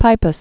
(pīpəs), Lake